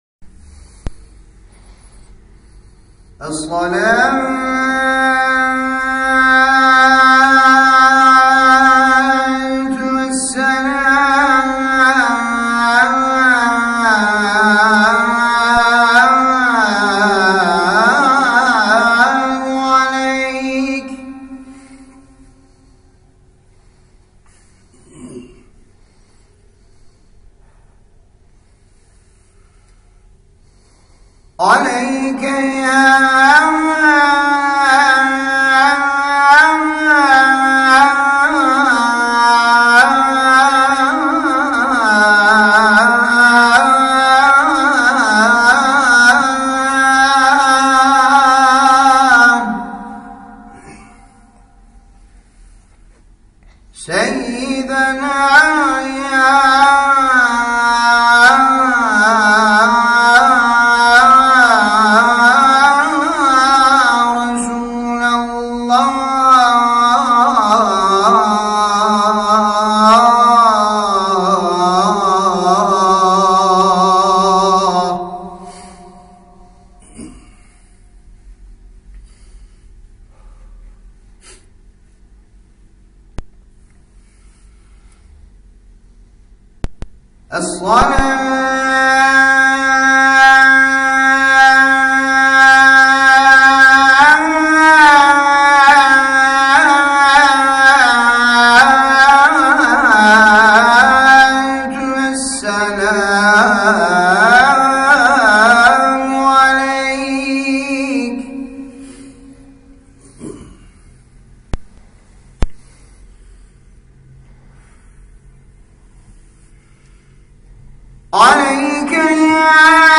Cuma ve Cenaze Namazlarından Önce Okunan Selanın Arapça Okunuşu
sela.mp3